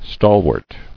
[stal·wart]